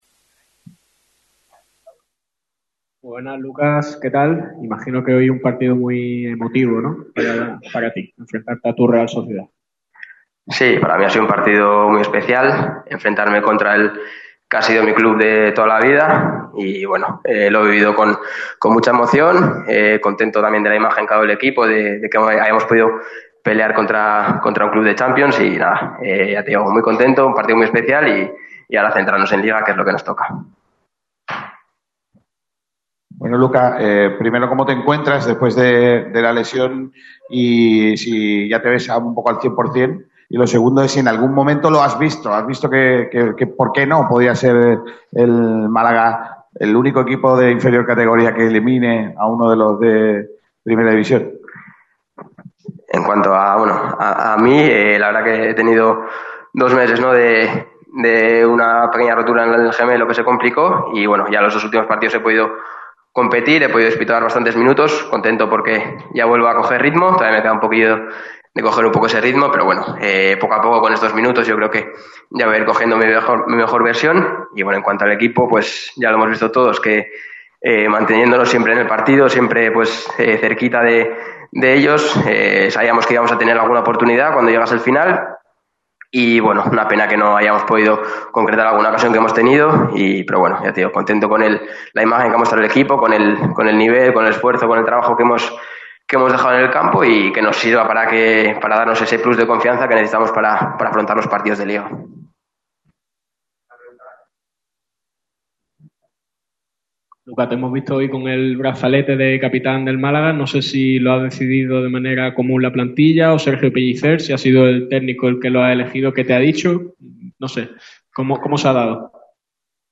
El centrocampista del Málaga CF ha comparecido ante los medios tras el partido ante su ex equipo, la Real Sociedad, que se ha saldado con victoria de los visitantes 0-1. El ’23’ habla de lo emotivo que ha sido este encuentro para él.